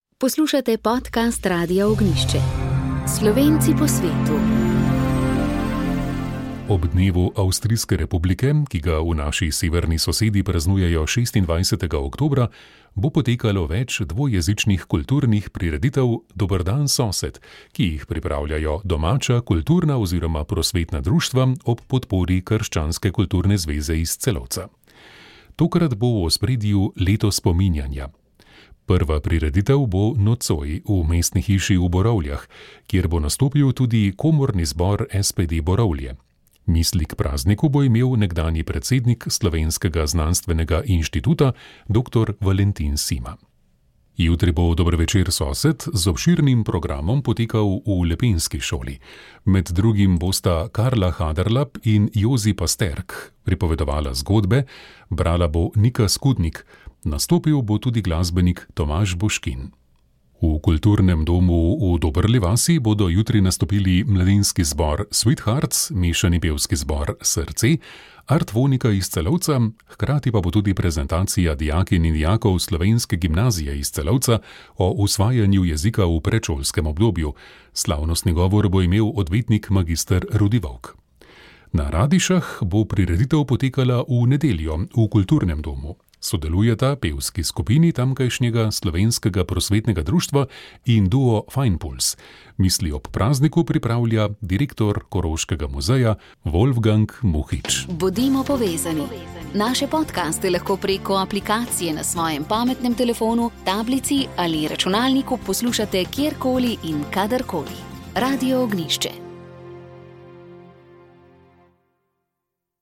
Vse to je zanimalo mlade na letošnji Dragi mladih. Prisluhnite, kako jim je odgovorila ministrica dr. Helena Jaklitsch.